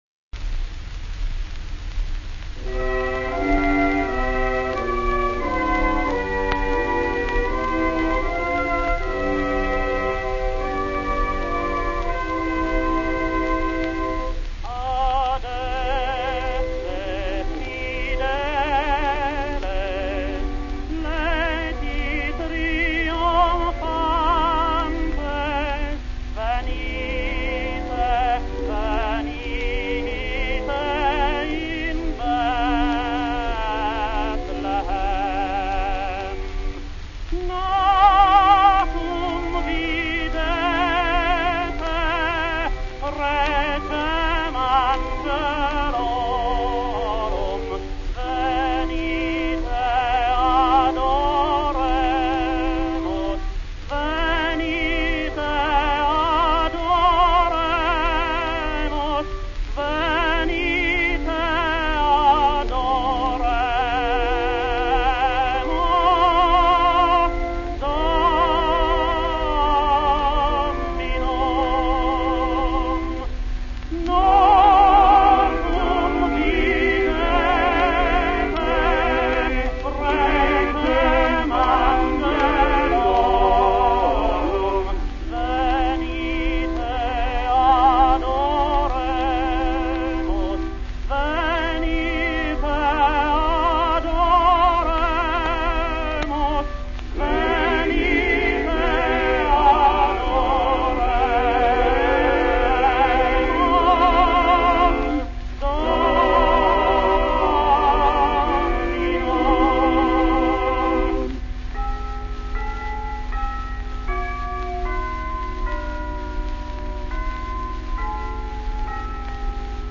John McCormack - irischer Tenor der in den 20ern in den USA sehr populär war.